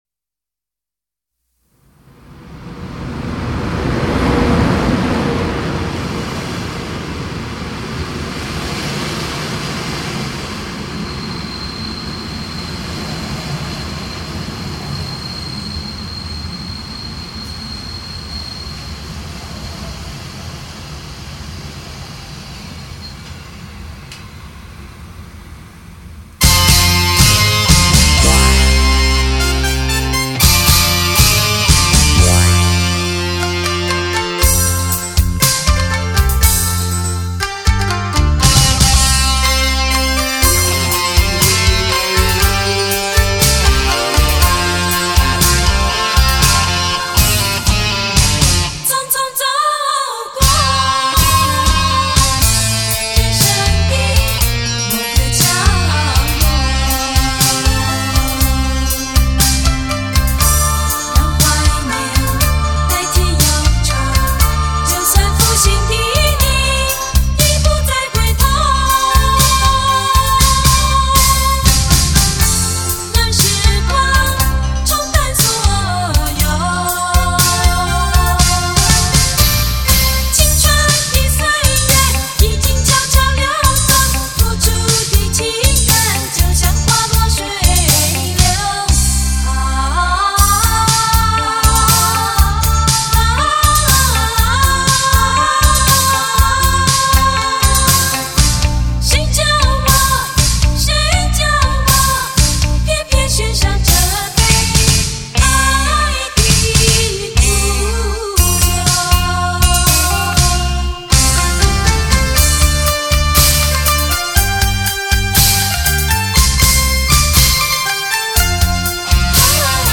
十女和声